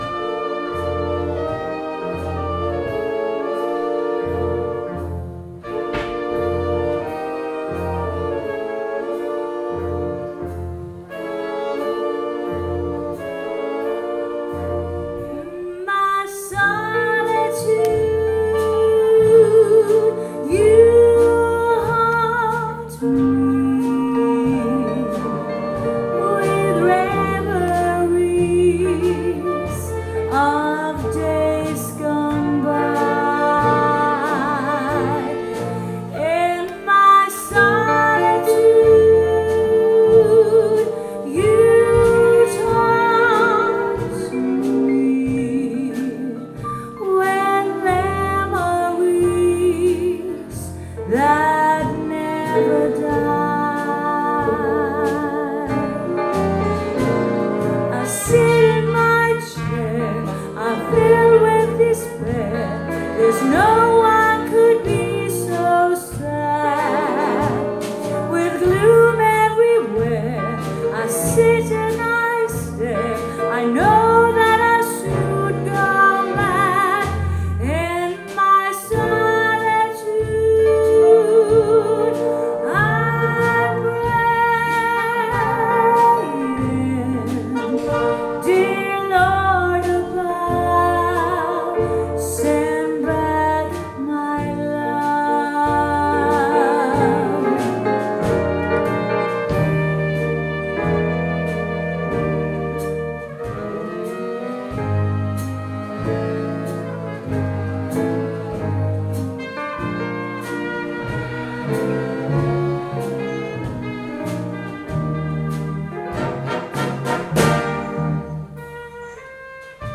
Big Band storband bröllop fest event party jazz